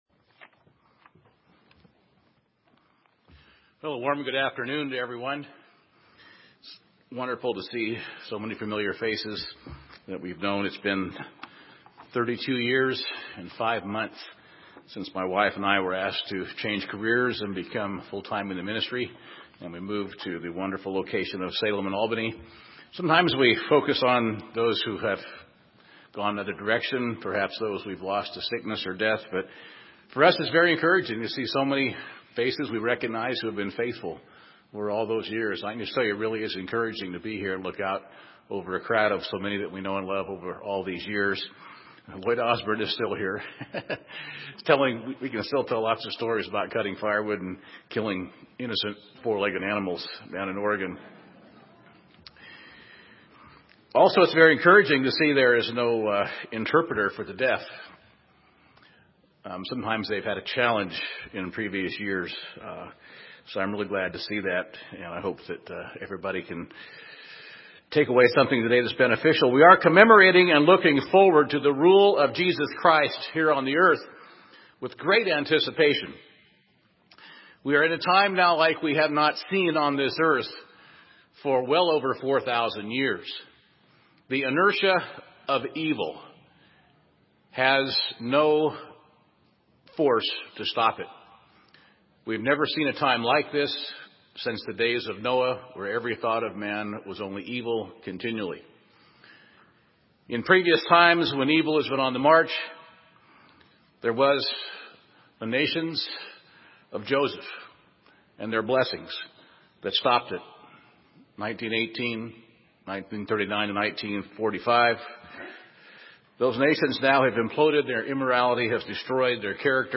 This sermon was given at the Bend, Oregon 2014 Feast site.